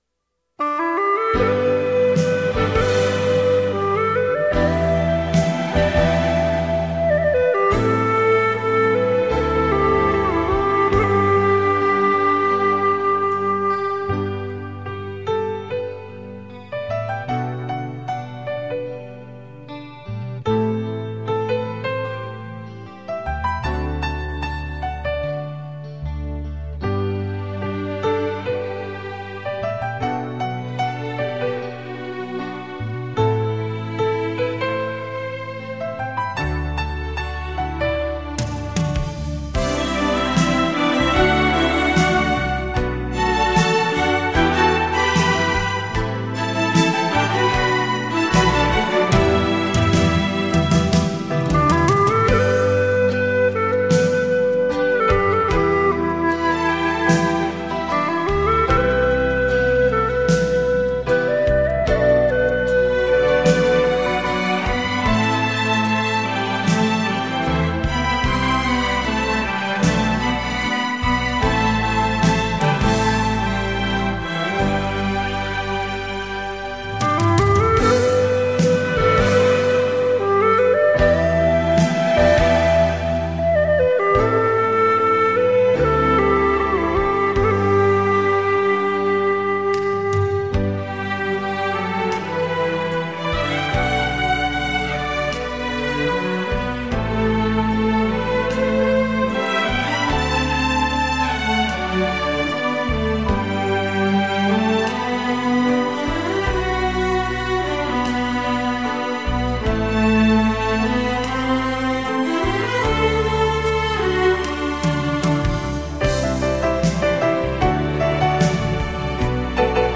身历其境的临场效果